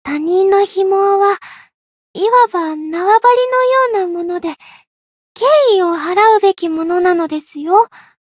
灵魂潮汐-伊汐尔-互动-厌恶的反馈.ogg